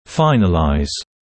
[‘faɪnəlaɪz][‘файнэлайз]завершать последнюю часть лечения, плана и т.п. (British English: finalise)